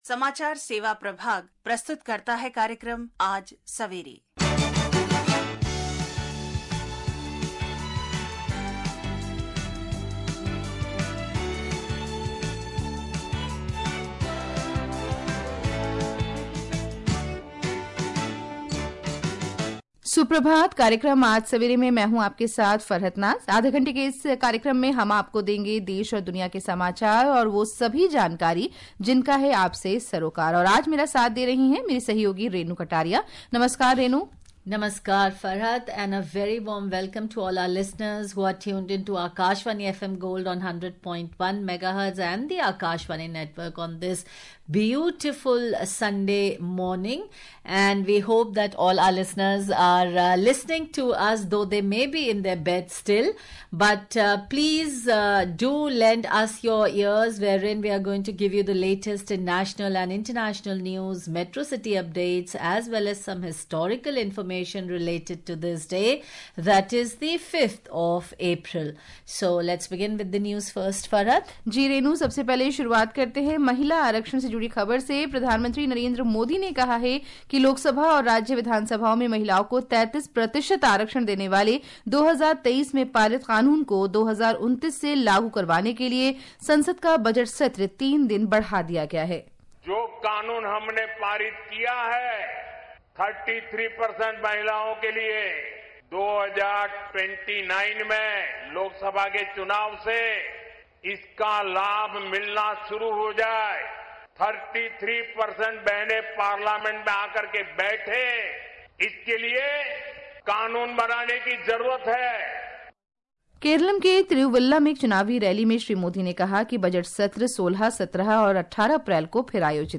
This audio news bulletin titled Hourly News in the category Hourly News .